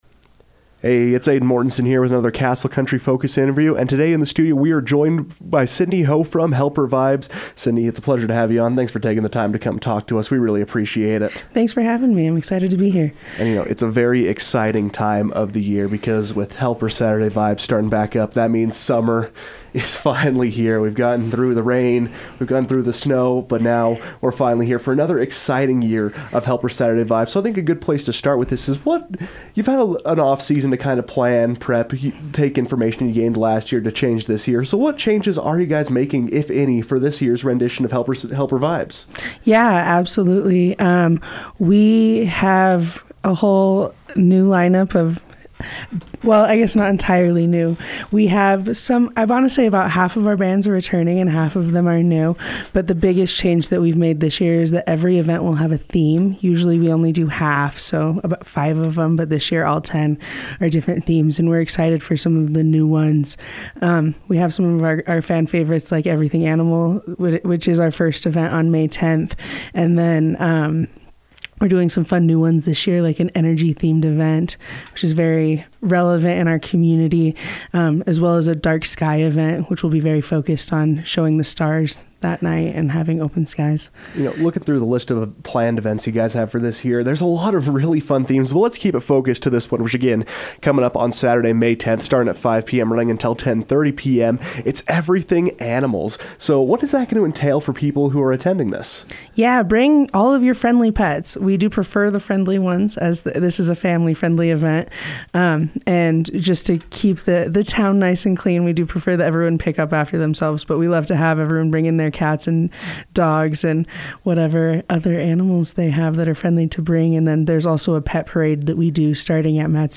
Opening our discussion